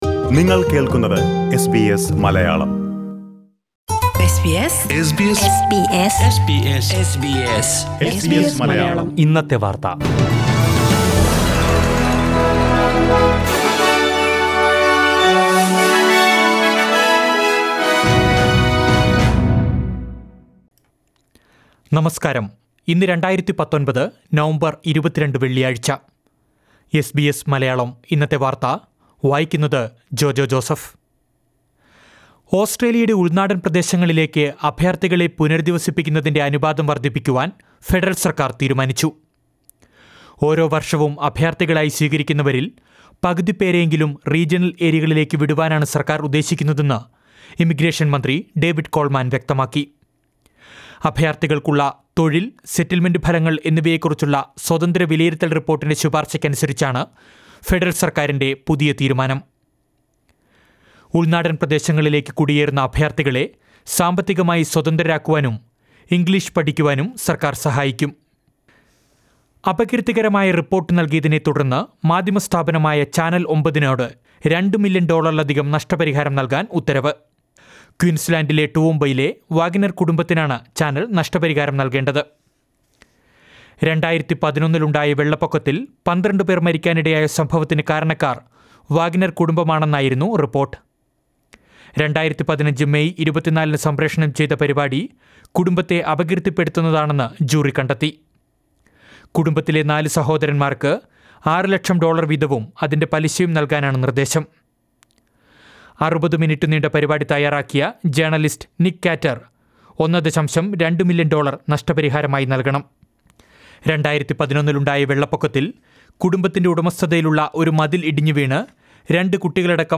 2019 നവംബര്‍ 22ലെ ഓസ്ട്രേലിയയിലെ ഏറ്റവും പ്രധാന വാര്‍ത്തകള്‍ കേള്‍ക്കാം